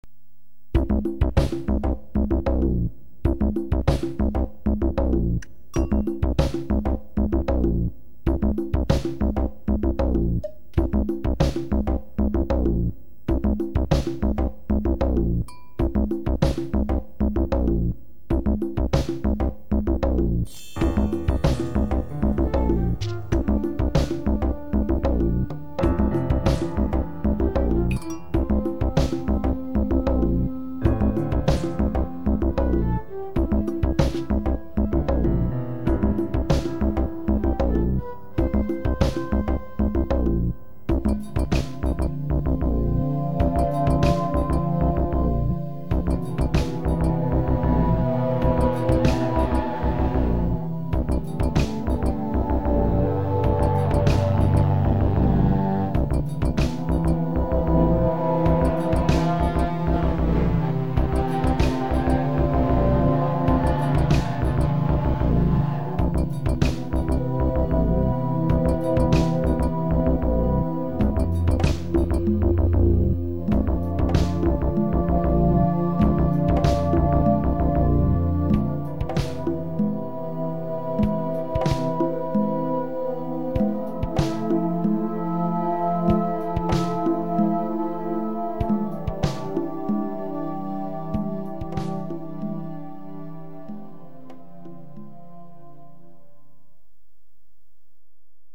間借り　DTM シーケンス